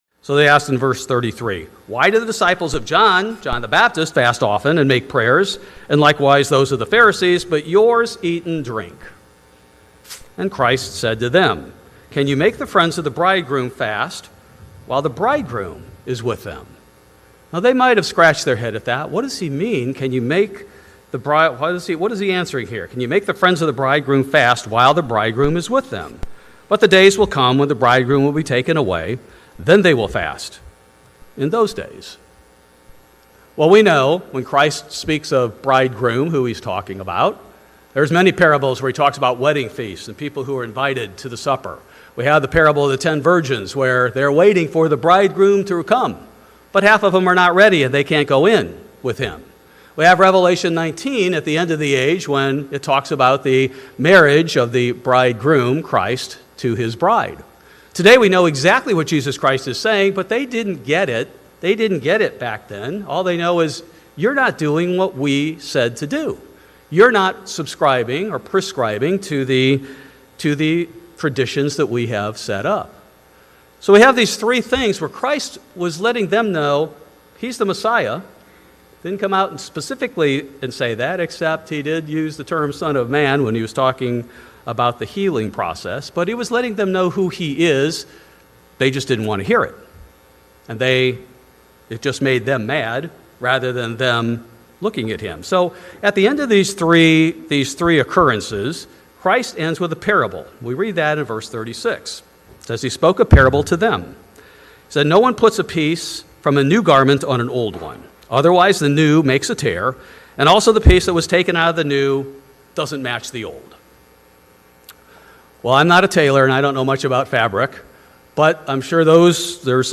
Please note that due to electrical storm interruption during the sermon, about 8 minutes from the beginning of this sermon was lost.